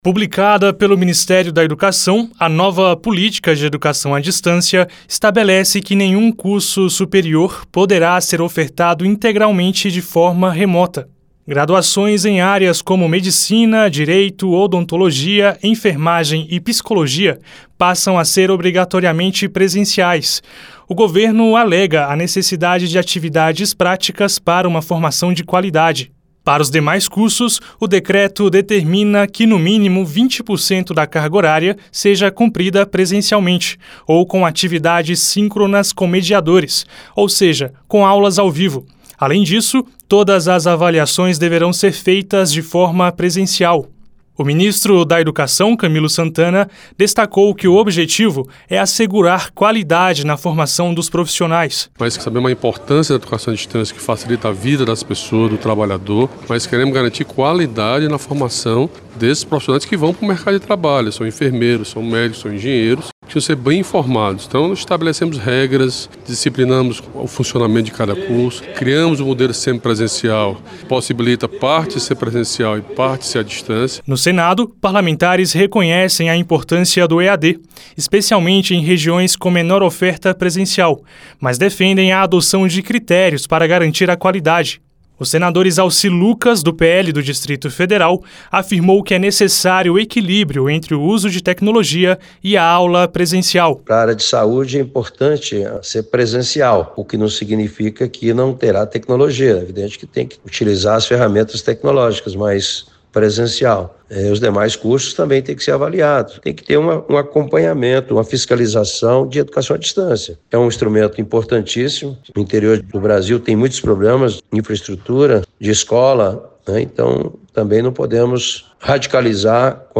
O Ministério da Educação publicou novas regras para o ensino superior a distância, proibindo a oferta 100% remota e restringindo cursos como Medicina, Direito e Enfermagem ao formato presencial. O ministro da Educação, Camilo Santana, reforça que o objetivo é assegurar a qualidade na formação profissional.
O senador Izalci Lucas (PL-DF) defende o uso equilibrado da tecnologia, com fiscalização adequada.